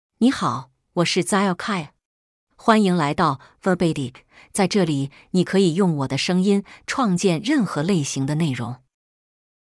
FemaleChinese (Mandarin, Simplified)
XiaoqiuFemale Chinese AI voice
Voice sample
Listen to Xiaoqiu's female Chinese voice.
Xiaoqiu delivers clear pronunciation with authentic Mandarin, Simplified Chinese intonation, making your content sound professionally produced.